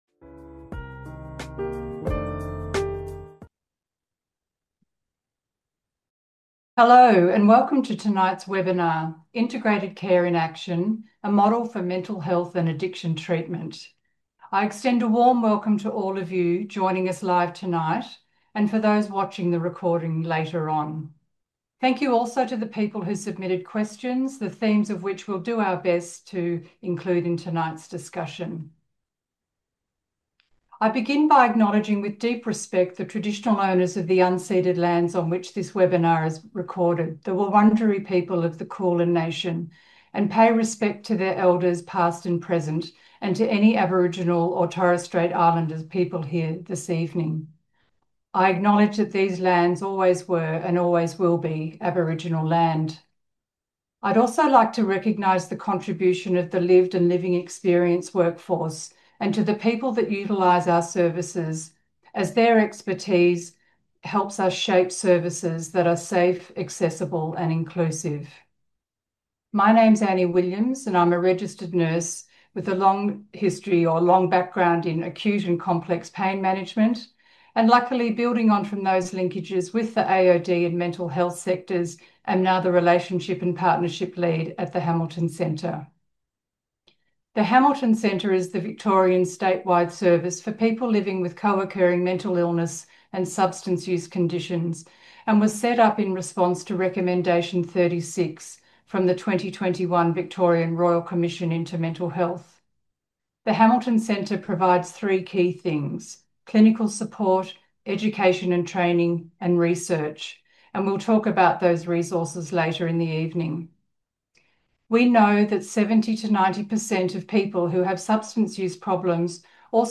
In this webinar, the panel identifies why and how an integrated interdisciplinary response supports better outcomes for Alcohol and other Drug presentations. This is done in the context of co-occurring and/or co-existing physical health, mental health, housing, forensic, interpersonal, vocational, and/or psychosocial challenges. The panel also offer tips and strategies that may mitigate the hurdles and maximise the enablers to integrated interdisciplinary care.